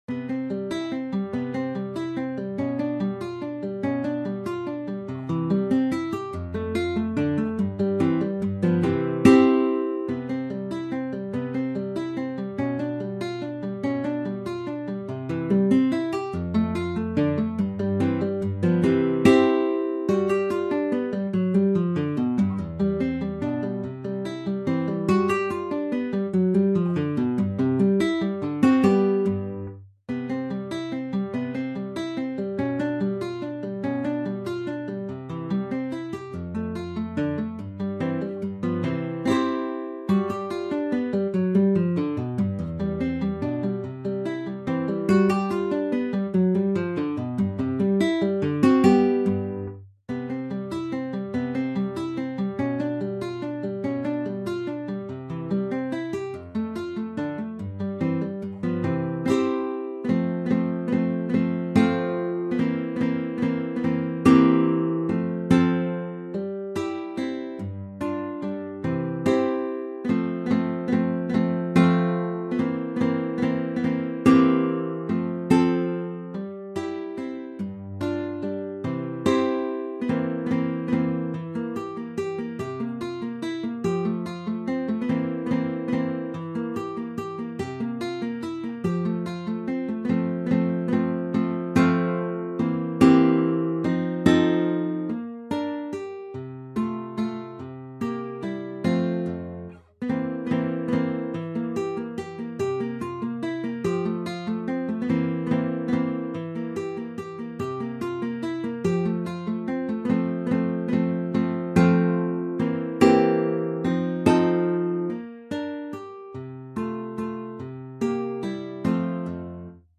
Carcassi, Matteo - 6 Waltzes, Op.4 Free Sheet music for Guitar
Instrument: Guitar
Style: Classical
6-waltzes-op-4.mp3